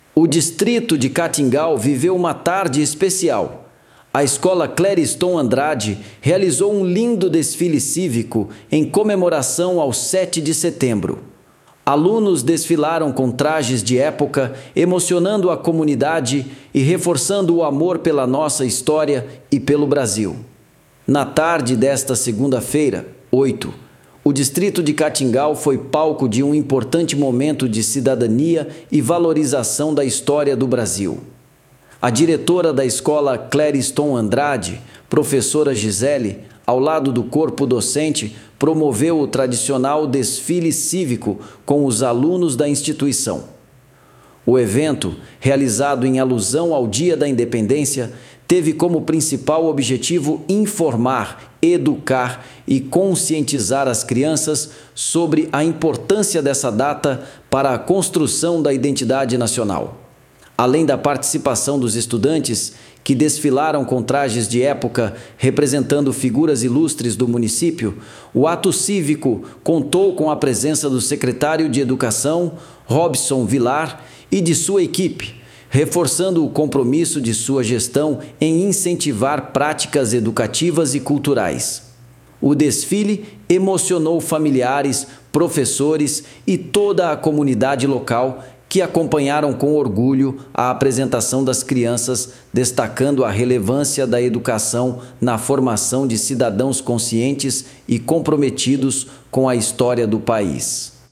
Reportagem.wav